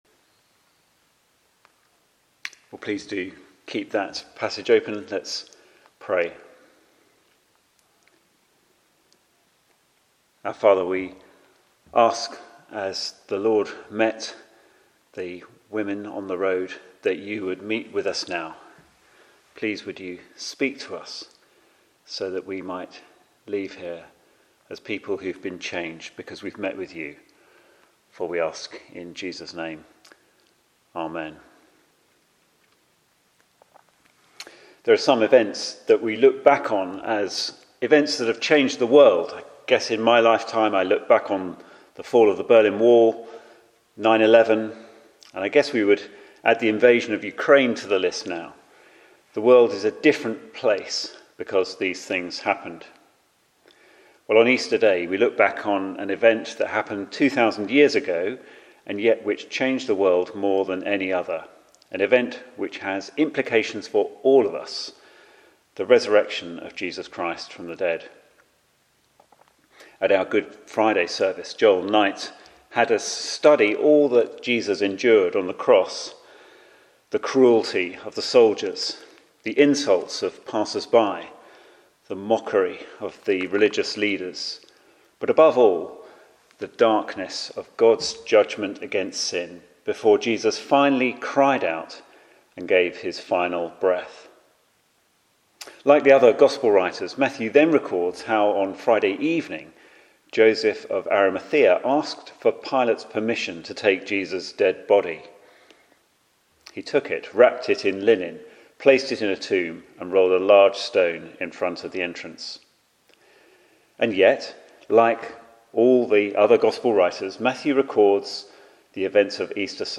Media for Sunday Evening on Sun 09th Apr 2023 18:00 Speaker